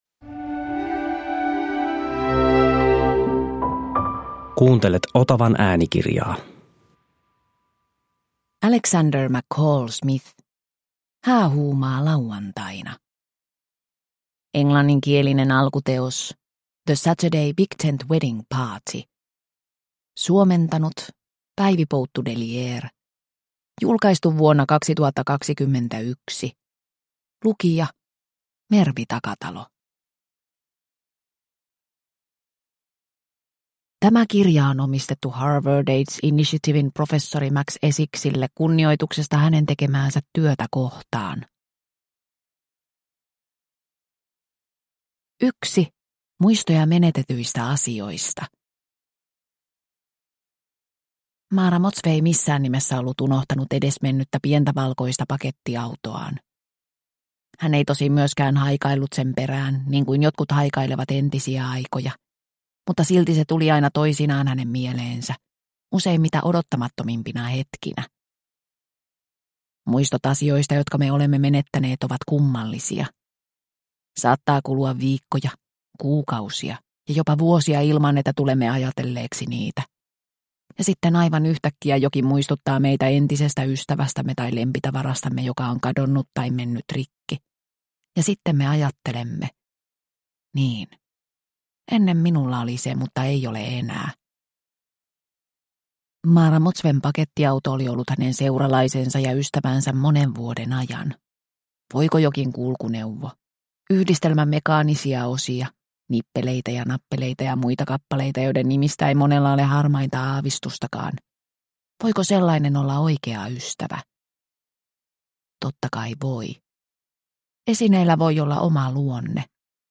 Häähuumaa lauantaina – Ljudbok – Laddas ner